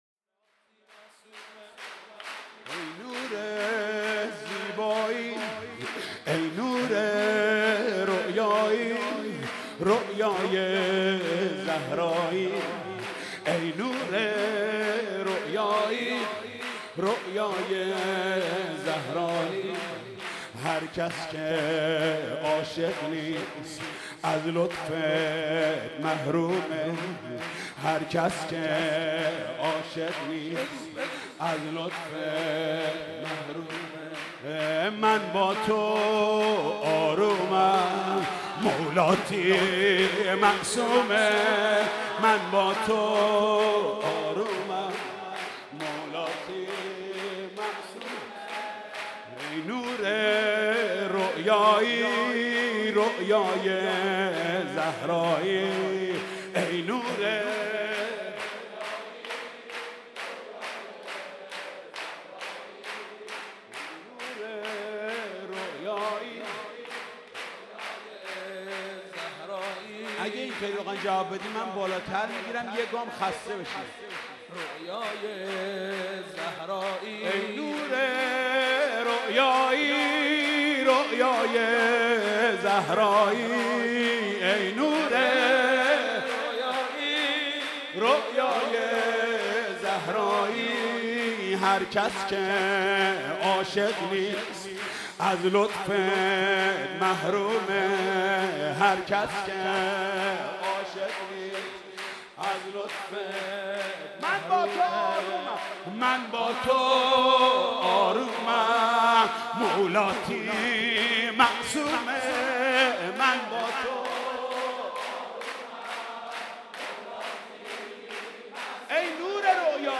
سرود: ای نور رویایی، رویای زهرایی